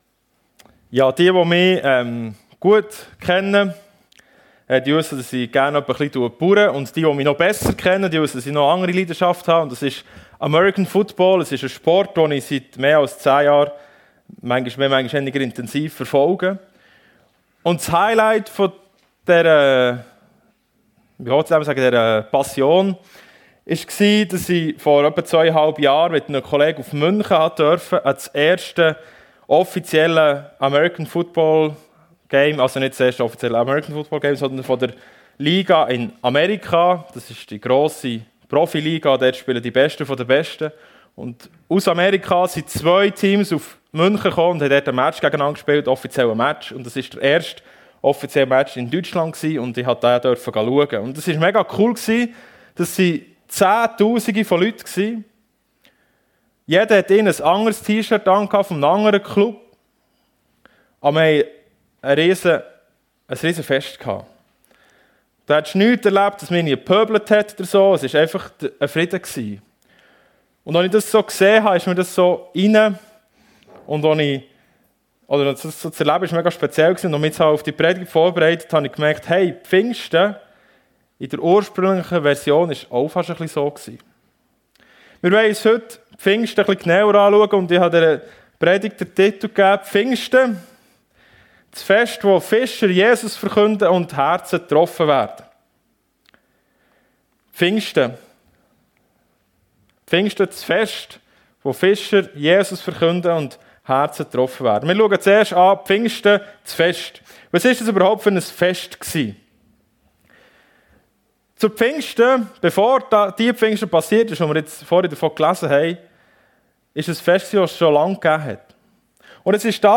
Pfingsten: Das Fest wo Fischer Jesus verkünden und Herzen getroffen werden ~ FEG Sumiswald - Predigten Podcast